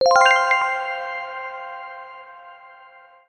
Category 🎮 Gaming
accomplished energy finished game object positive success win sound effect free sound royalty free Gaming